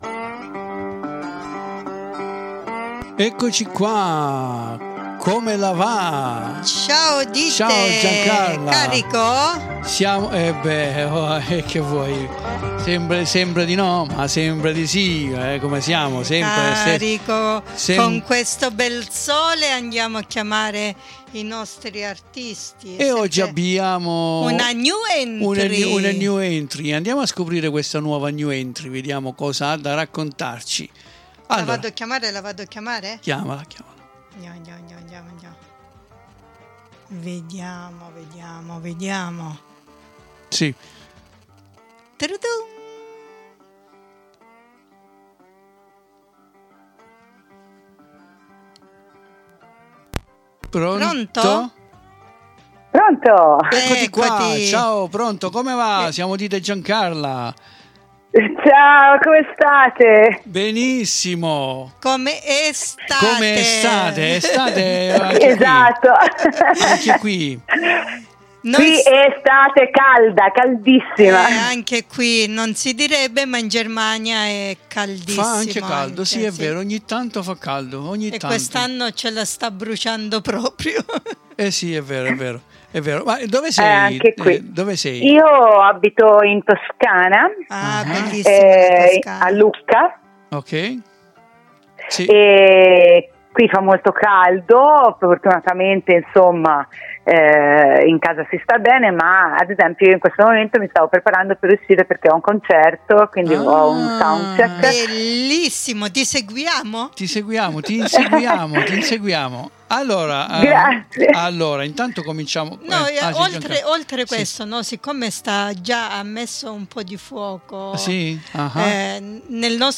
NON SPIFFERO NULLA PERCHÉ VI VOGLIO LASCIARE IL BUON ASCOLTO A QUESTA INTERESSANTISSIMA INTERVISTA, CONDIVISA QUI IN DESCRIZIONE.